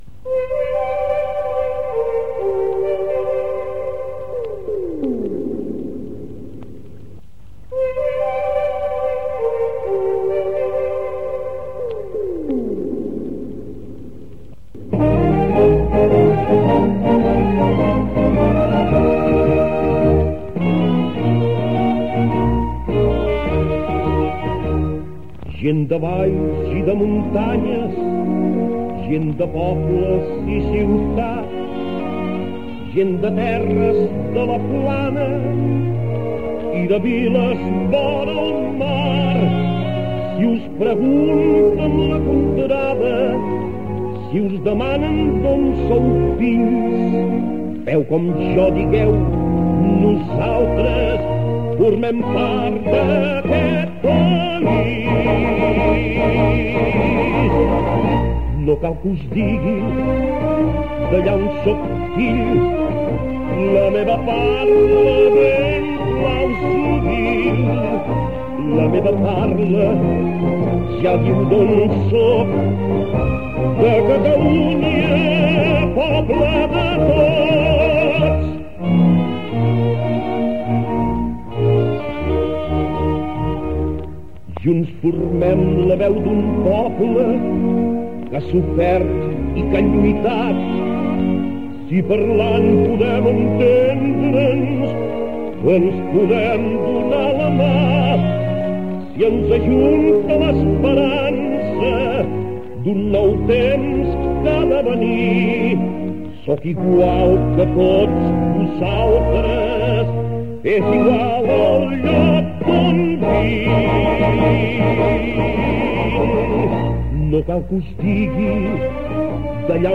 Inici d'emissió amb la cançó "Poble de tots"
FM